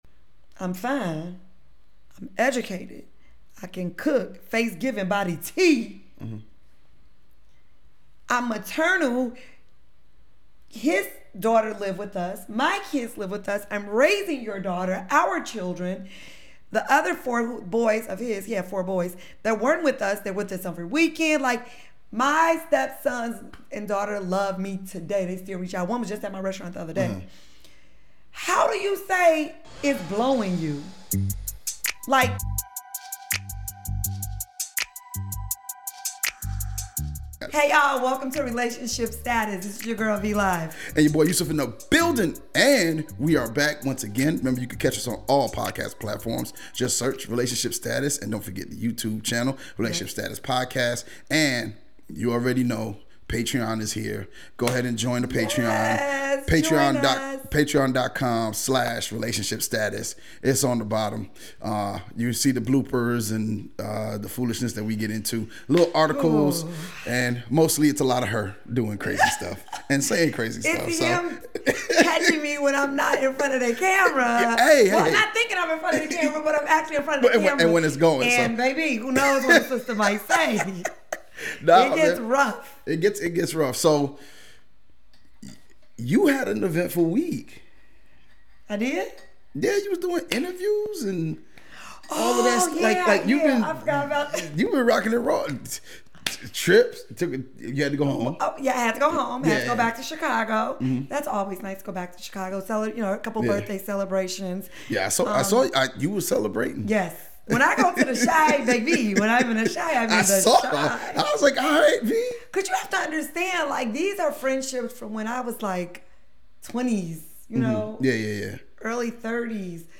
Tune in for honest conversations about self-awareness, compatibility, and maintaining healthy relationships.